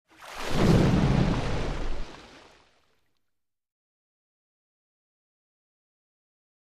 Underwater Pressure Push Impact With Bubble Movement